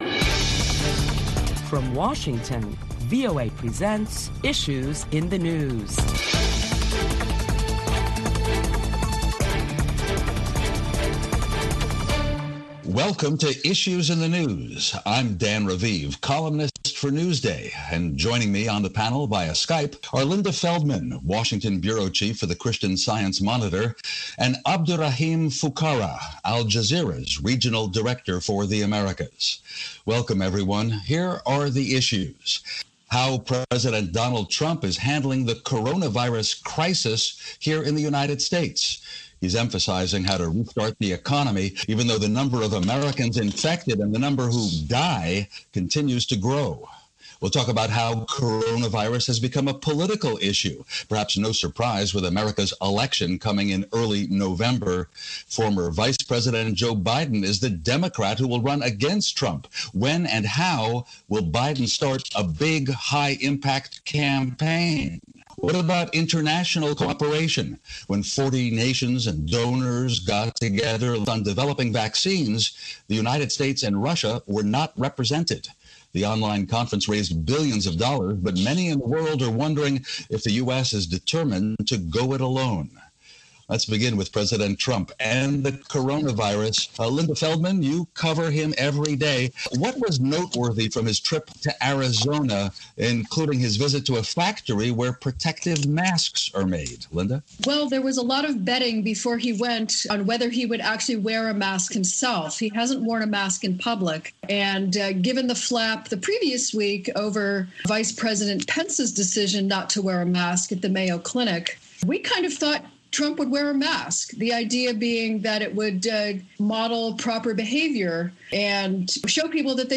Listen to Issues in the News where you will hear a panel of prominent Washington journalists deliberate the latest top stories of the week.